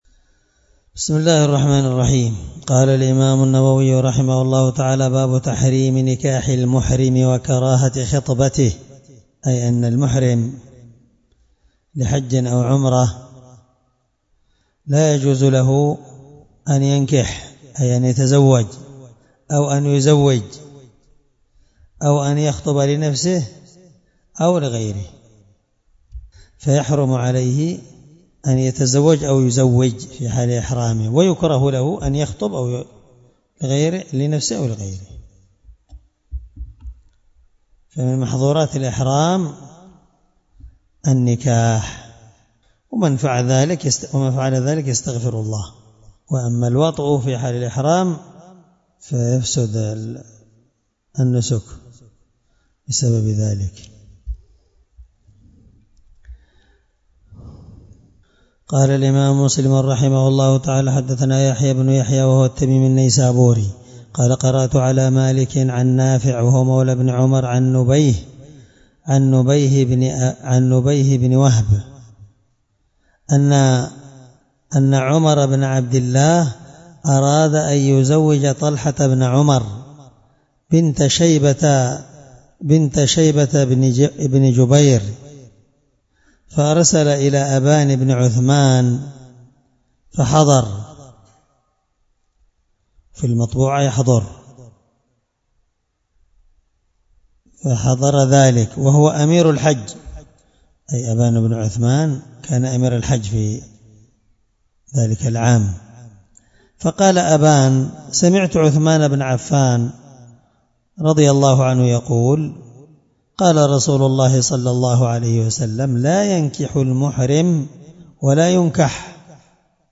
الدرس11من شرح كتاب النكاح حديث رقم(1409) من صحيح مسلم